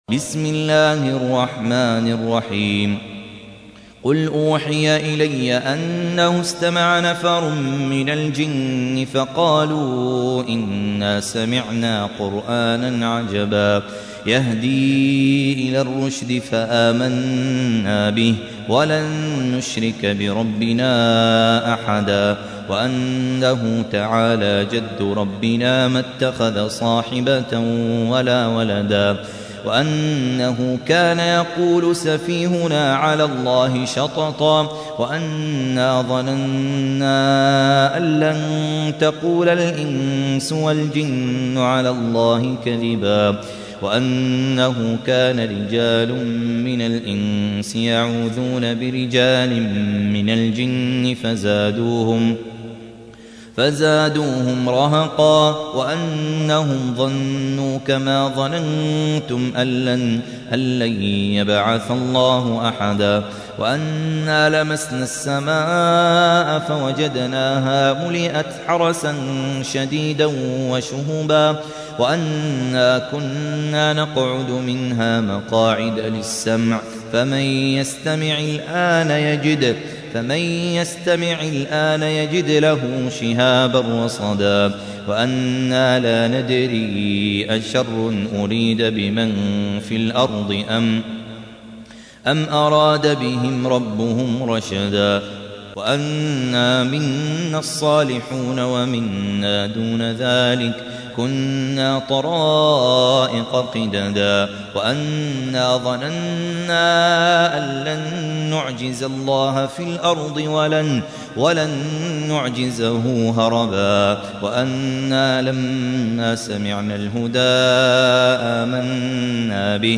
تحميل : 72. سورة الجن / القارئ خالد عبد الكافي / القرآن الكريم / موقع يا حسين